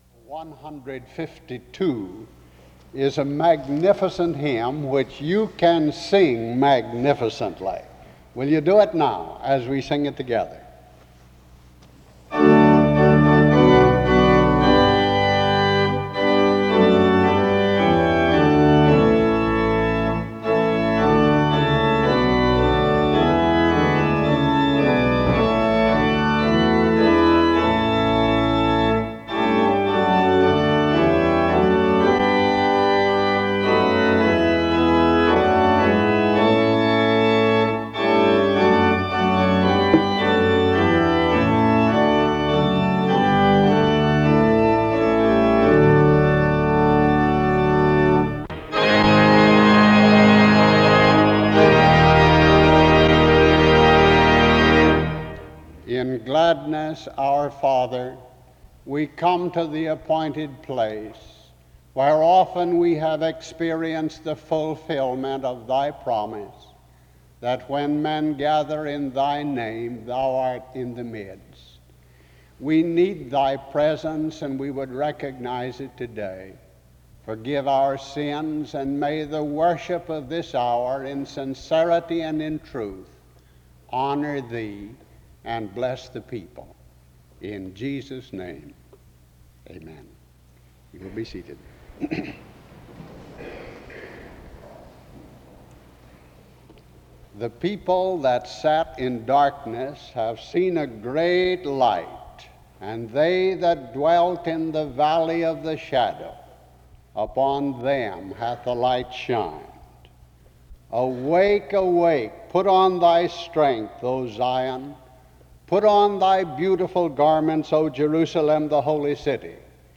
The service opens with a hymn from 0:00-0:55. A prayer is offered from 0:56-1:30.
Special music plays from 3:53-10:17.
The service closes with music from 26:39-27:35.
SEBTS Chapel and Special Event Recordings SEBTS Chapel and Special Event Recordings